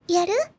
menu-freeplay-click.ogg